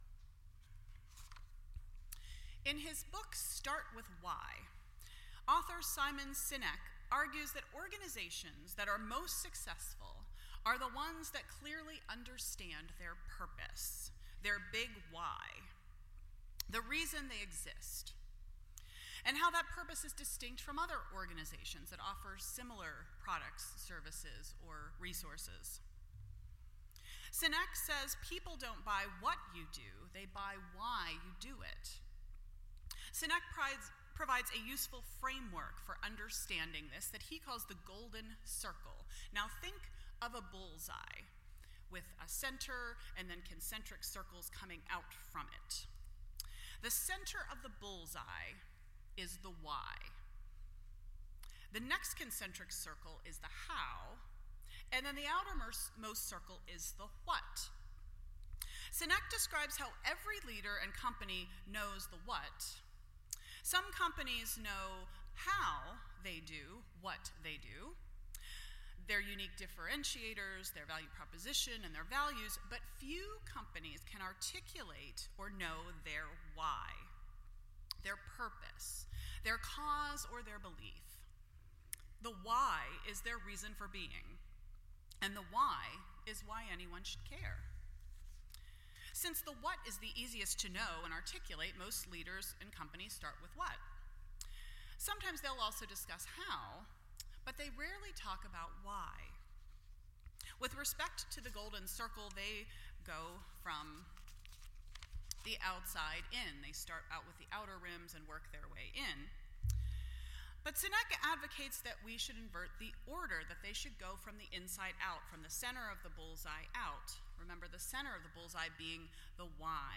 Foundations Service Type: Sunday Morning %todo_render% Share This Story